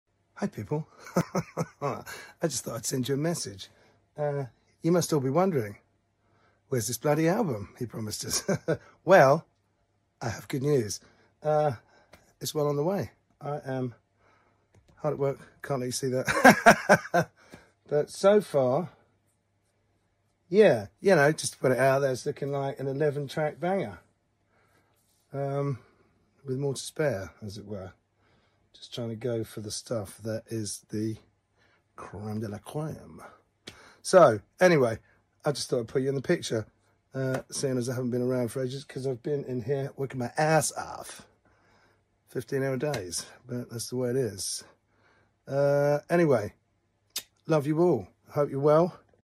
An album update live and sound effects free download By jamiroquai_hq 537 Downloads 11 months ago 49 seconds jamiroquai_hq Sound Effects About An album update live and Mp3 Sound Effect An album update live and direct from the studio!